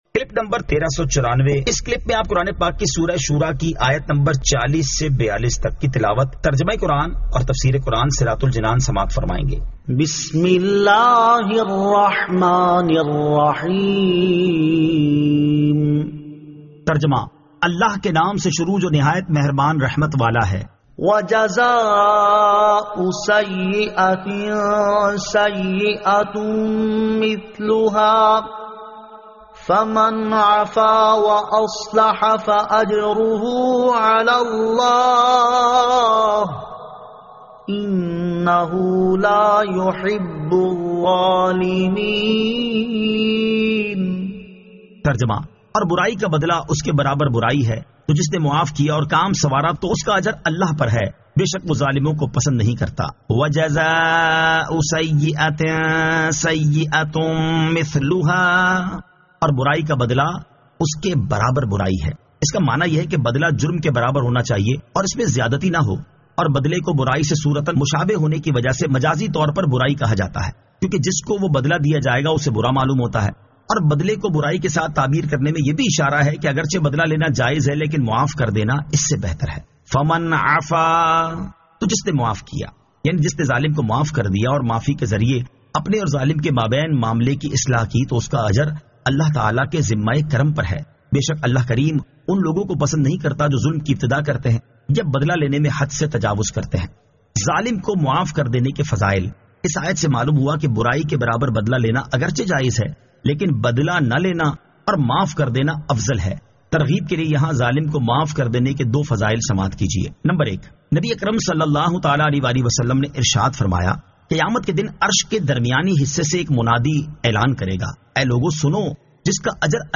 Surah Ash-Shuraa 40 To 42 Tilawat , Tarjama , Tafseer